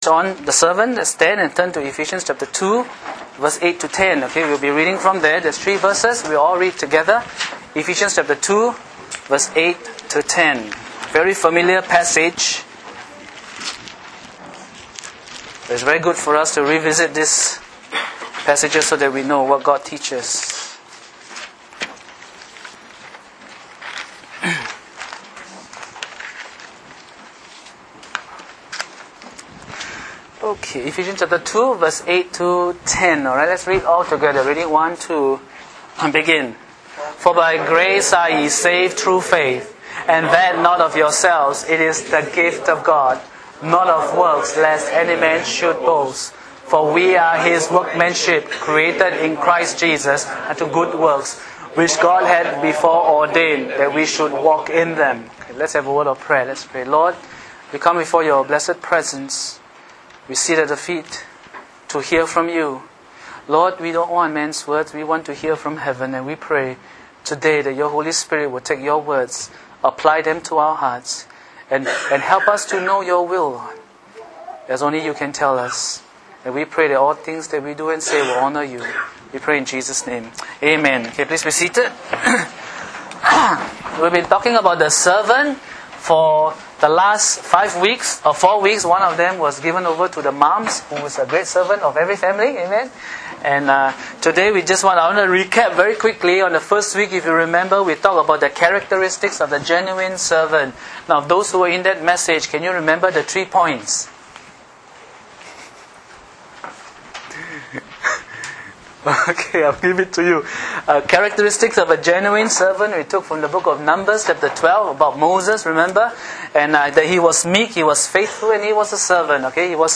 Welcome to Ambassador Baptist Church !